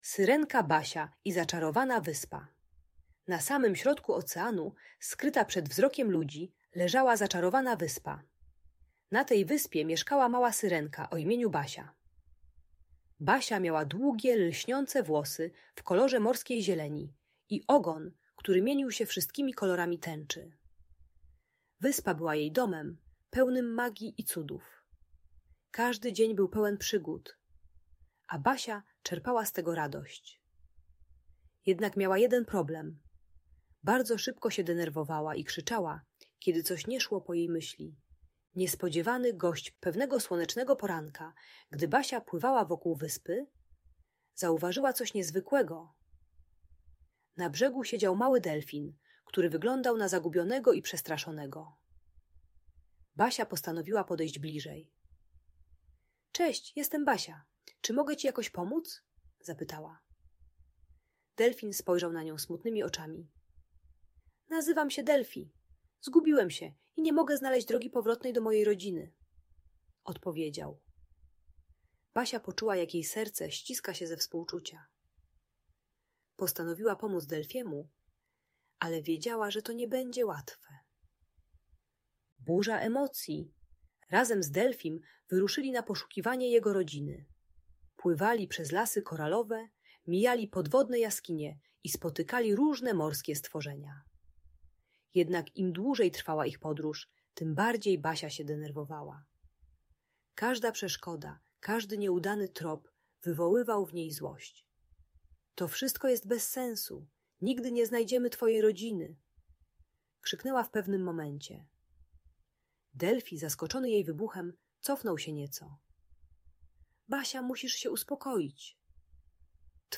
Syrenka Basia i Zaczarowana Wyspa - Bajka o emocjach - Audiobajka